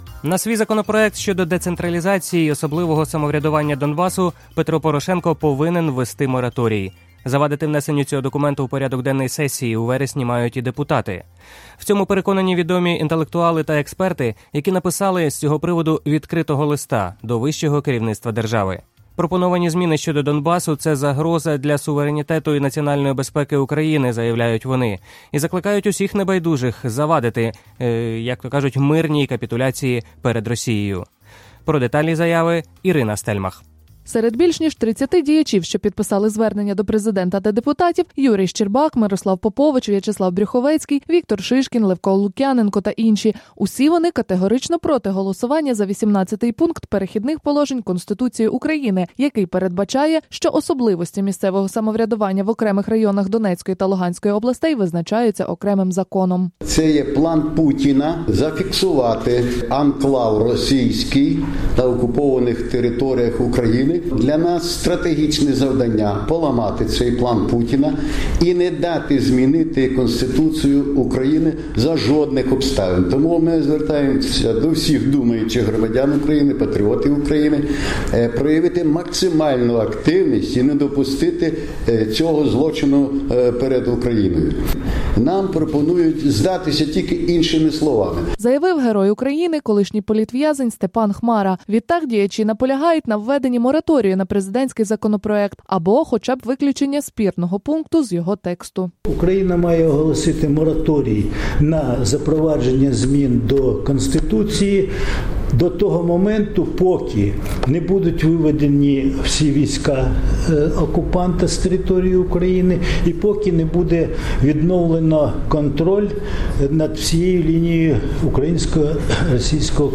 Прес-конференція з вимогою ввести мораторій на зміни до Конституції в частині децентралізації, Київ, 13 серпня 2015 року